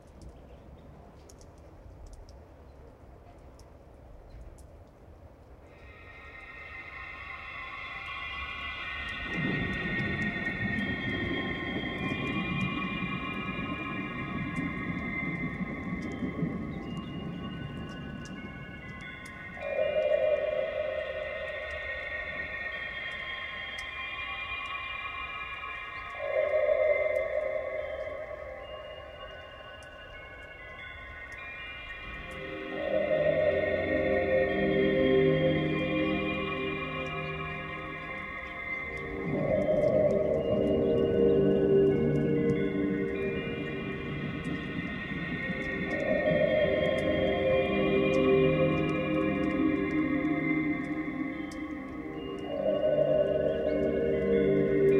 Levyllä myös mm. kitaraa ja huiluja.